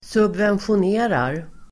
Uttal: [subvensjon'e:rar]